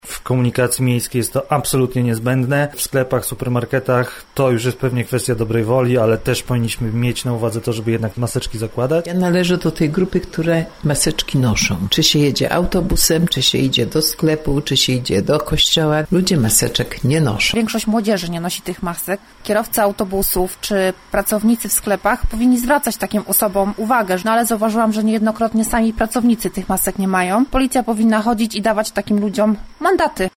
Zapytaliśmy także kilku zielonogórzan czy pamiętają o zakrywaniu ust i nosa: